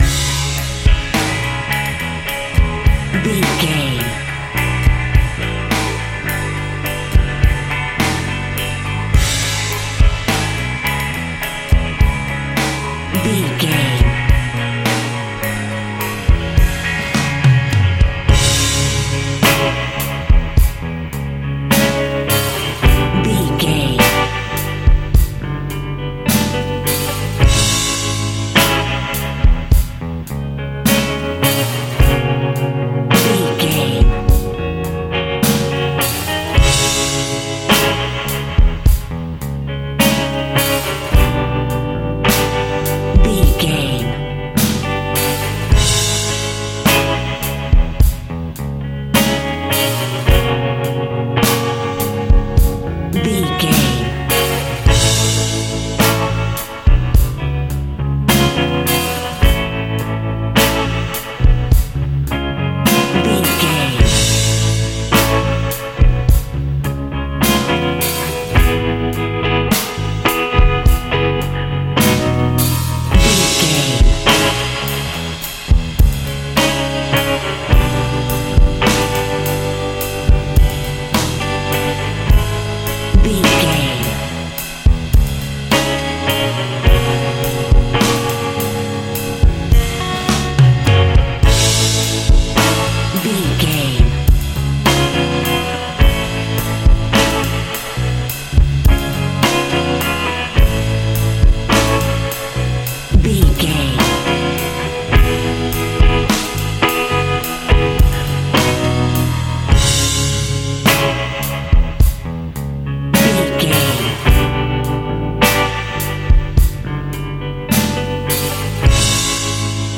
Aeolian/Minor
D♭
uplifting
bass guitar
electric guitar
drums
cheerful/happy